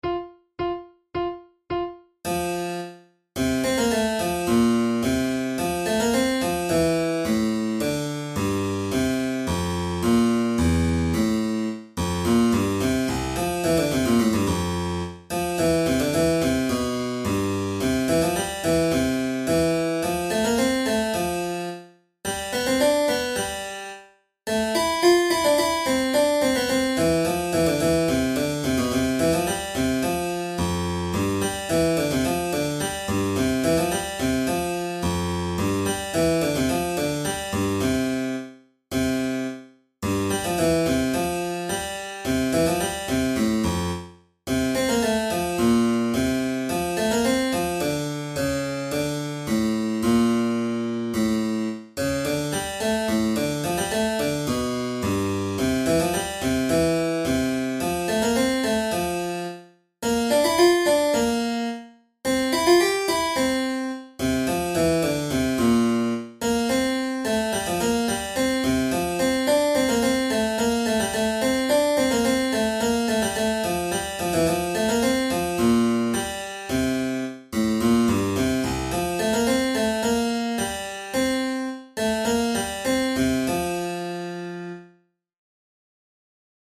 Benedetto Marcello's Sonata in F major for treble recorder and figured bass is particularly noteworthy for its highly idiomatic writing for the recorder; nonetheless, this work also sounds very good when played on the flute, and can indeed be found in some of the numerous collections of baroque music compiled for our instrument.
Categories: Baroque Sonatas Difficulty: intermediate